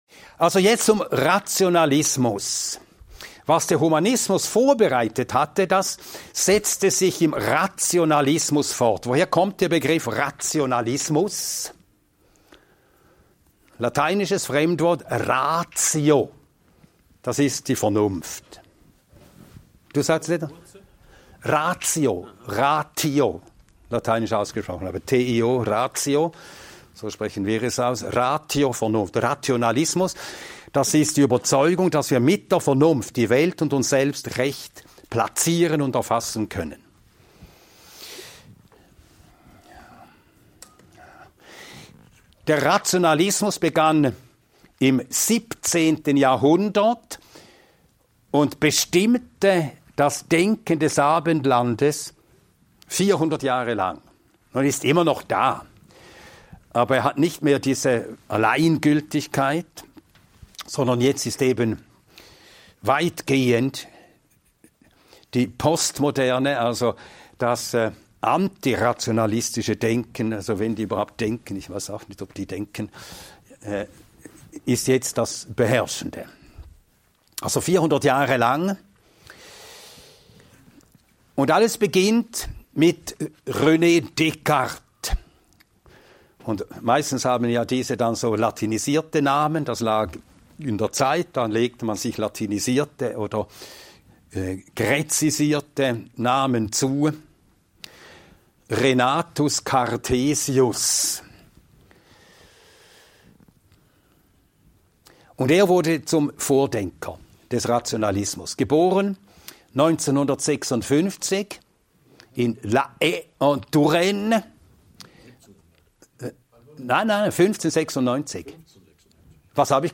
In diesem Vortrag erfährst du, warum der Rationalismus nicht das hält, was er verspricht – und warum wir ohne Gottes Wort in Einsamkeit und Verzweiflung enden. Der Rationalismus hat das westliche Denken seit Jahrhunderten geprägt – mit René Descartes als Schlüsselfigur.